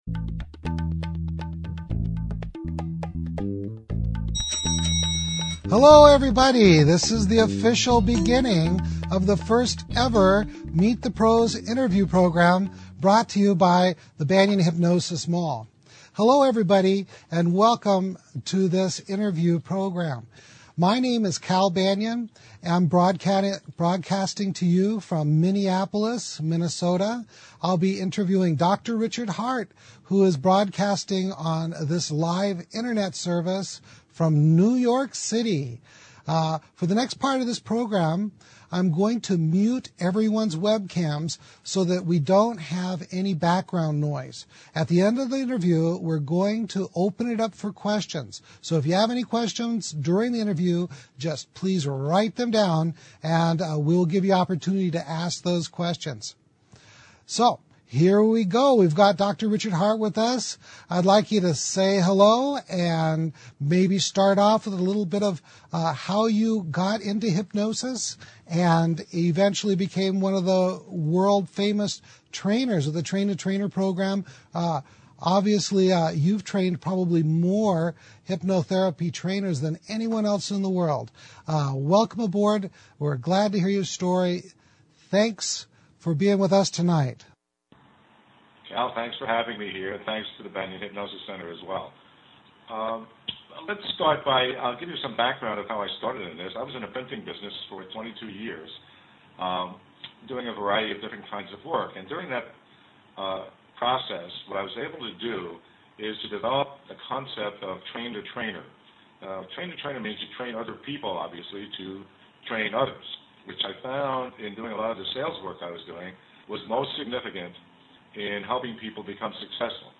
I hope you will enjoy my interview